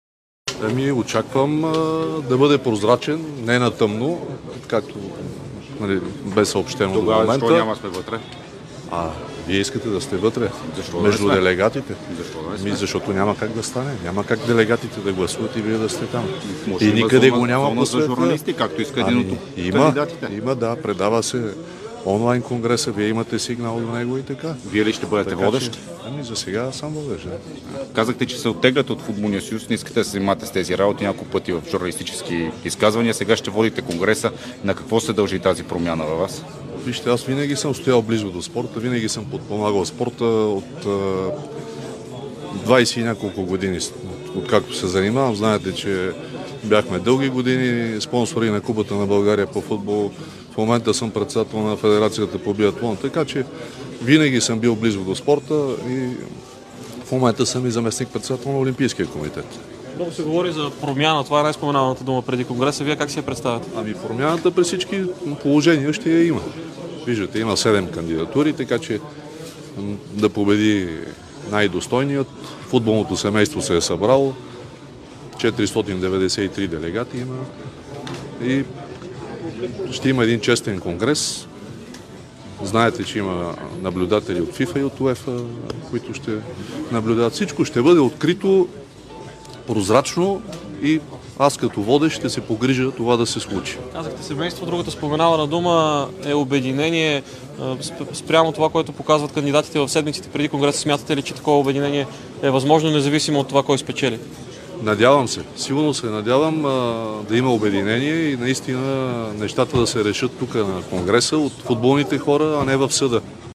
Той говори пред журналистите, като разкри, че има 493 делегата, като увери, че конгресът ще бъде честен.